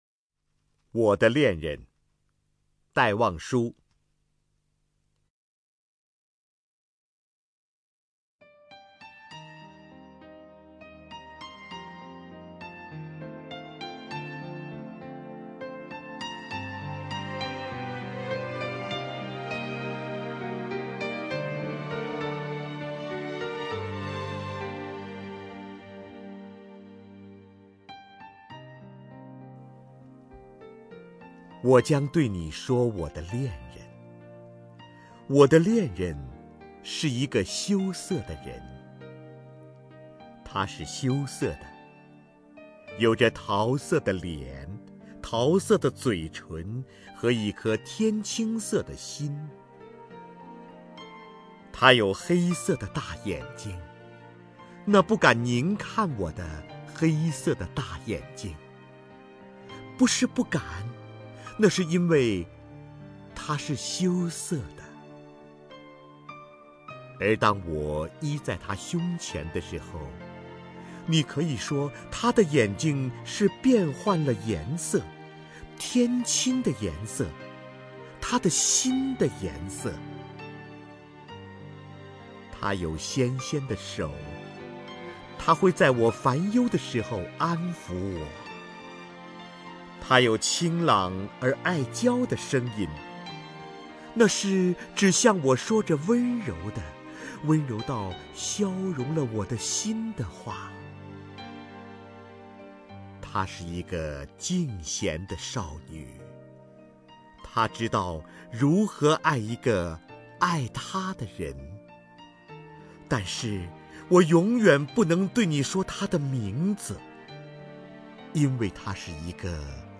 首页 视听 名家朗诵欣赏 瞿弦和
瞿弦和朗诵：《我的恋人》(戴望舒)